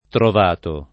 trovato [ trov # to ]